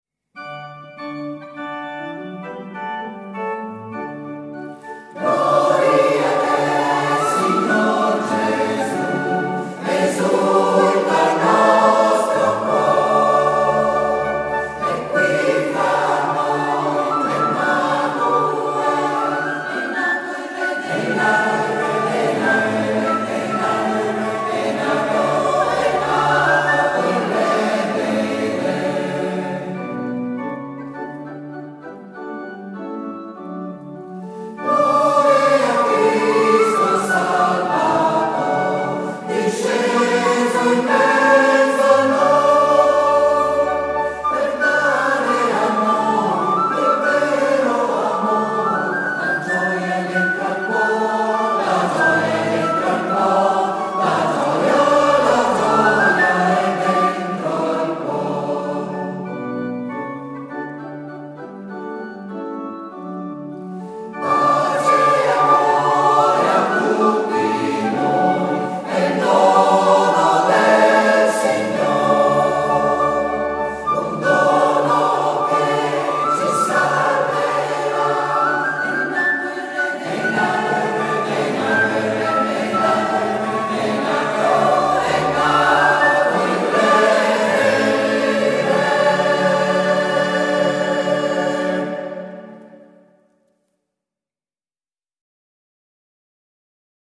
AUGURI di BUONE FESTE e arrivederci al 10 gennaio 2022 Joy to the World! [coro Salus Puer i]